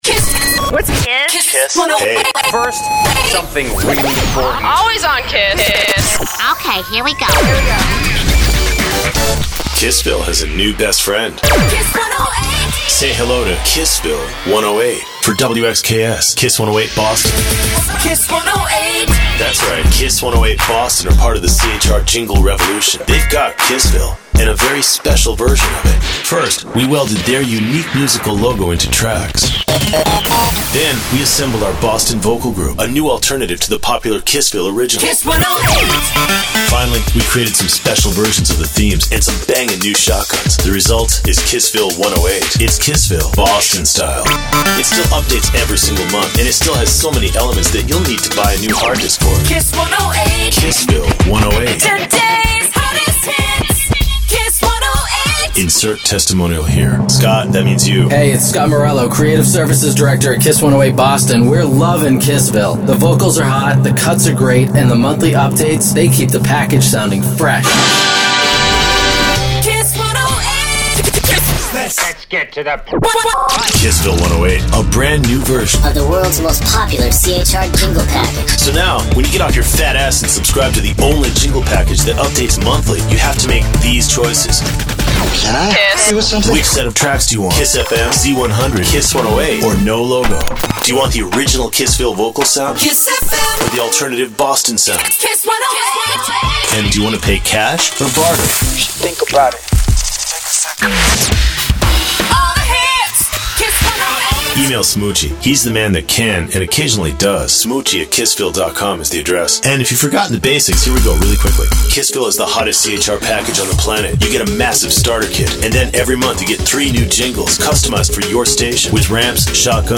the CHR Jingle package that updates every month